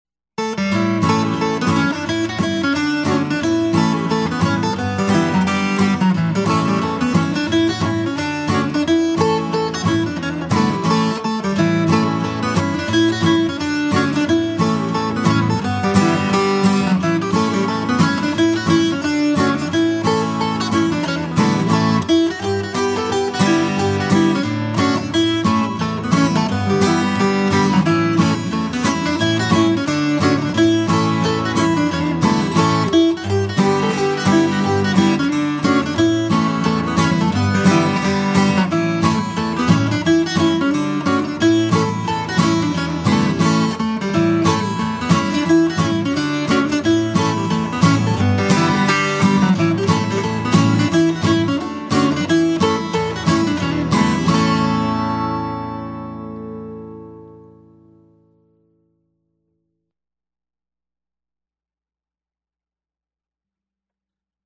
DIGITAL SHEET MUSIC - FLATPICK/PLECTRUM GUITAR SOLO